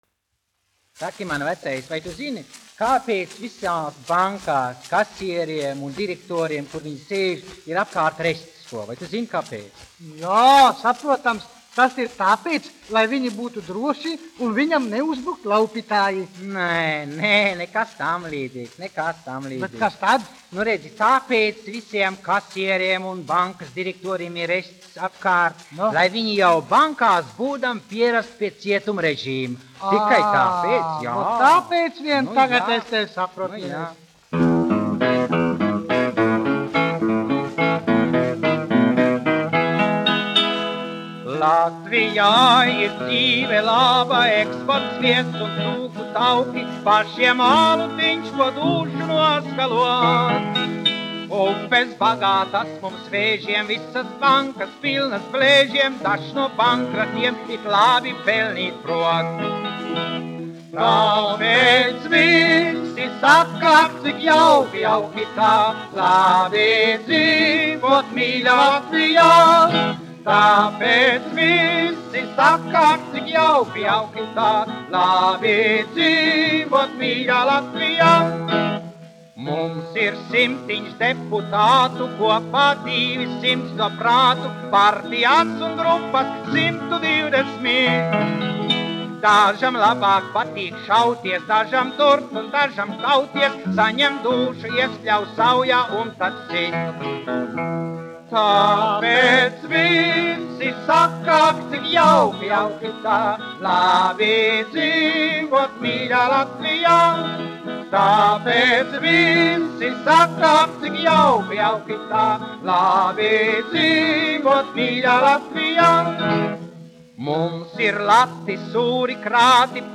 1 skpl. : analogs, 78 apgr/min, mono ; 25 cm
Humoristiskās dziesmas
Skaņuplate